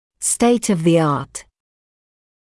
[steɪt ɔv ðɪ ɑːt][стэйт ов зи аːт]самый современный уровень (техники, науки)